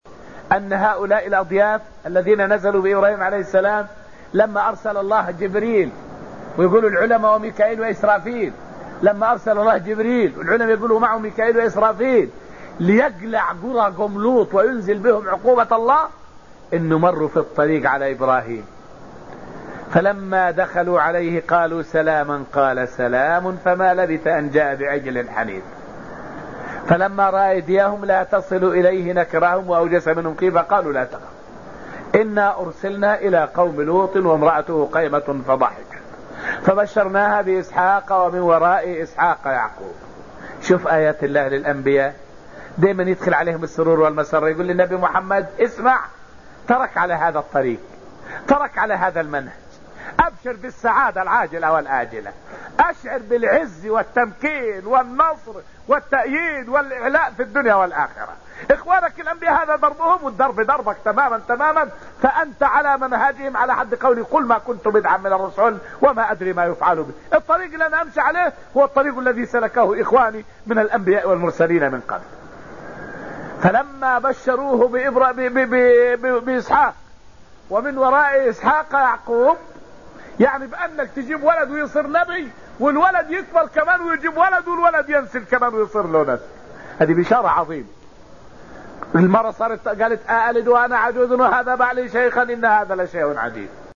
فائدة من الدرس السابع من دروس تفسير سورة القمر والتي ألقيت في المسجد النبوي الشريف حول بشارة الله لنبي الله إبراهيم بولده إسحاق.